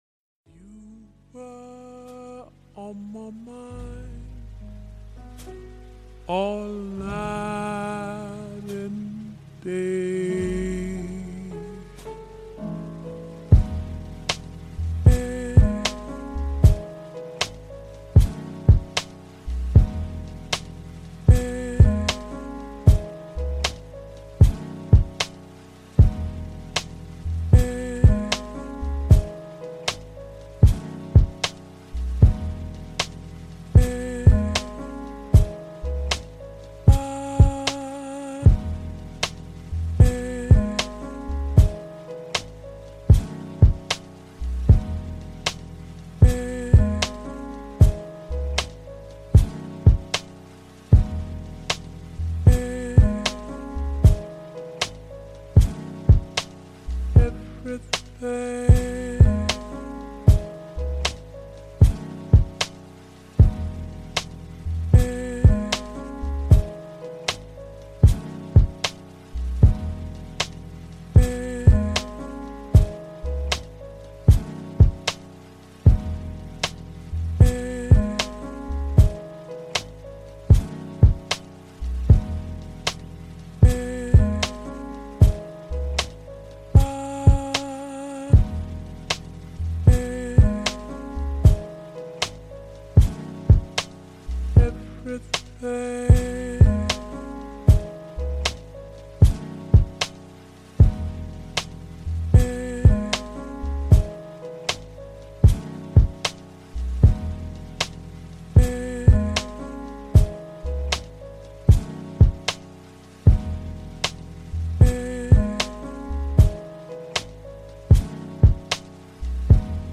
Lo-Fi Pluvieux : Concentration